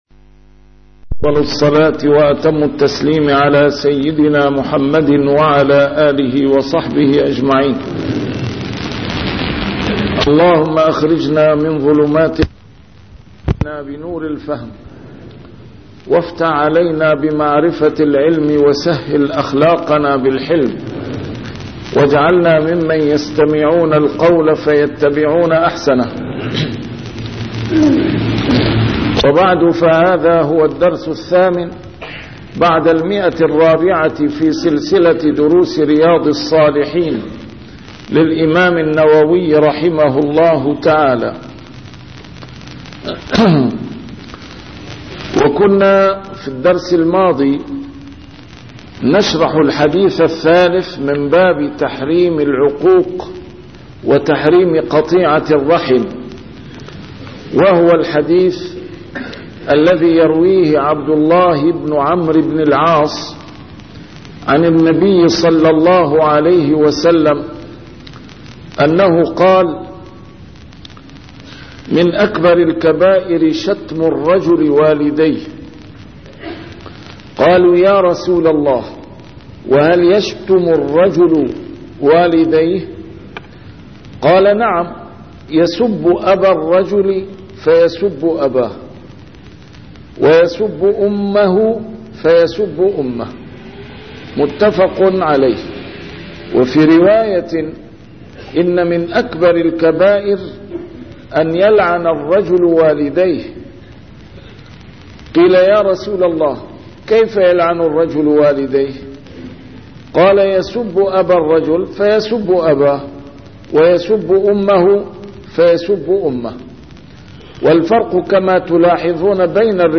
A MARTYR SCHOLAR: IMAM MUHAMMAD SAEED RAMADAN AL-BOUTI - الدروس العلمية - شرح كتاب رياض الصالحين - 408- شرح رياض الصالحين: تحريم العقوق وقطيعة الرحم